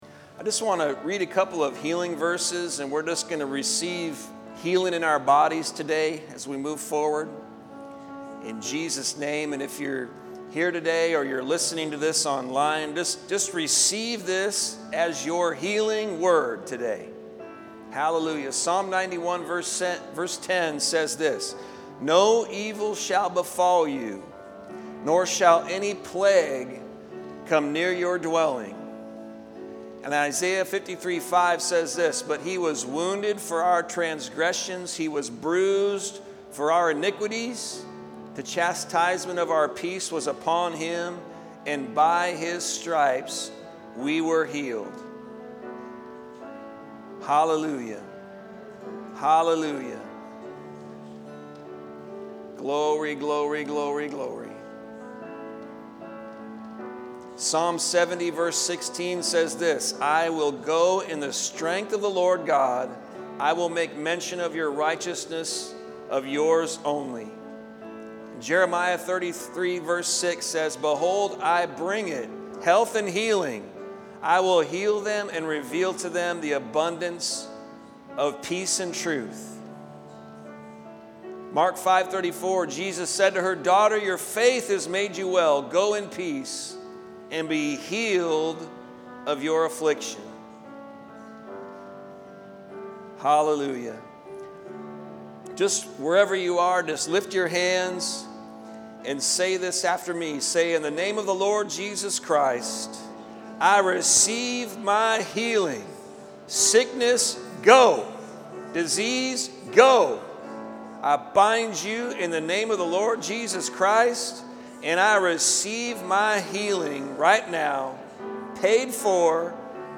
Prayer For Healing Visit our 'Connect' page to send any additional prayer requests AND we love to hear about answered prayer too!